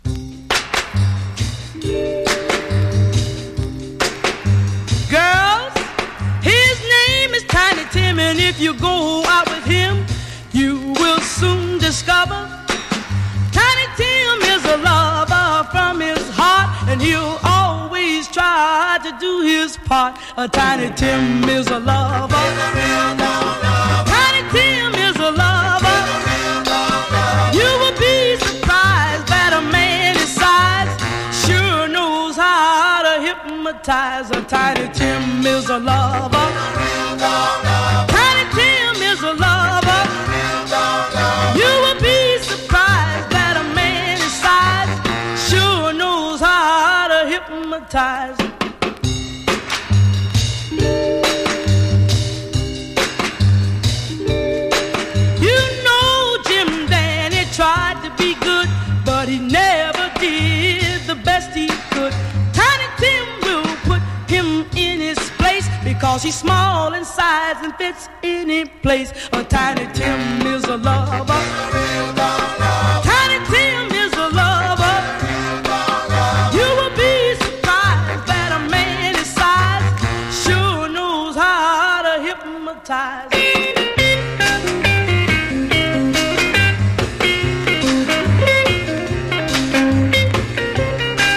パンチの効いたPOPCORN R&B VOCAL！